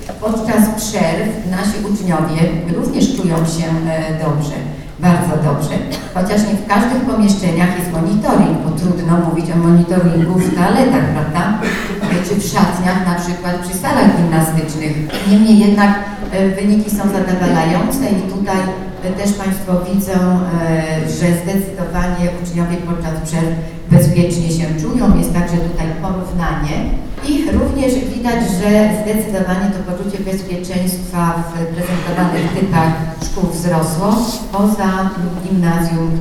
W niedzielę odbyła się w Żninie konferencja pod tytułem "Bezpieczna Gmina".
O szkołach  w całym województwie mówiła Kujawsko - Pomorska Kurator Oświaty Anna Łukaszewska.